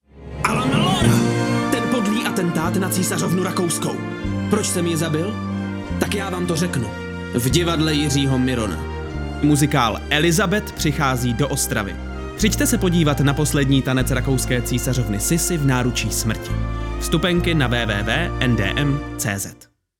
Oficiální audiospot NDM